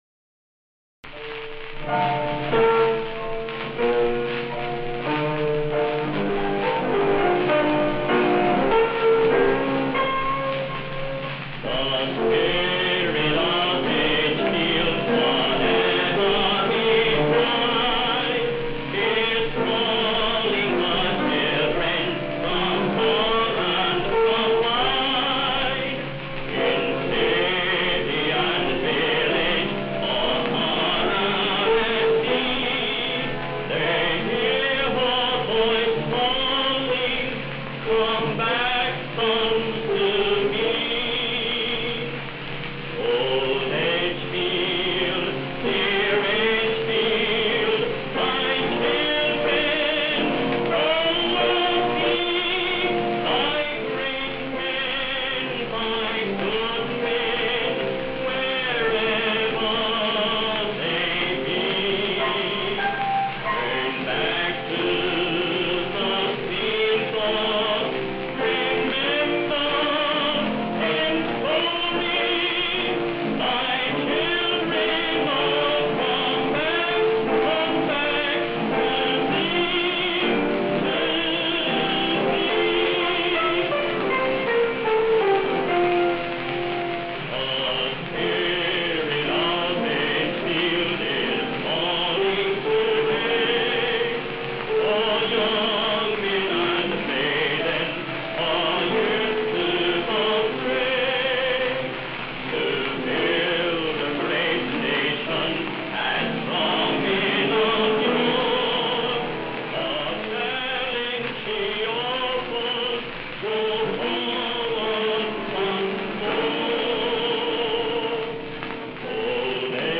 was found on an original 78 RPM record
recorded this with what was then relatively new recording technology.